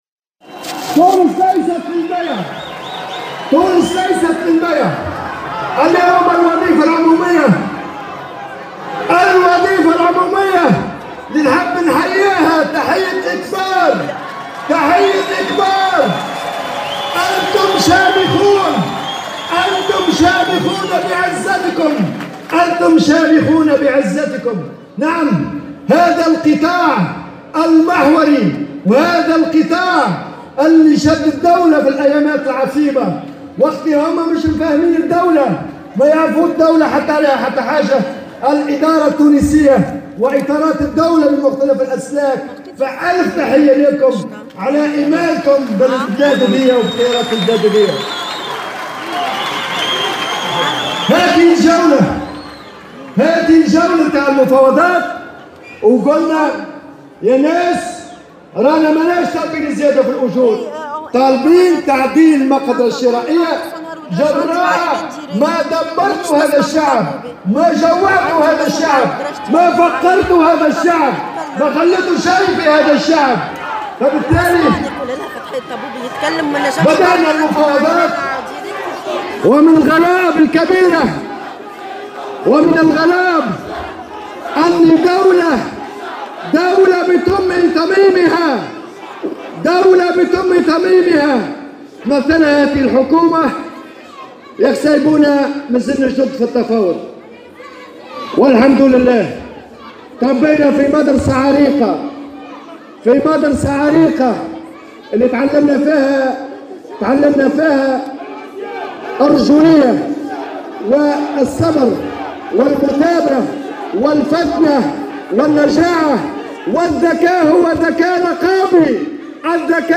قال الأمين العام لإتحاد الشغل نور الدين الطبوبي خلال الكلمة التي ألقاها بساحة محمد علي اليوم إن تونس ليست للبيع مؤكدا أن المفاوضات فشلت لأن الحكومة أرادت تقديم أرقام مغلوطة للرأي العام .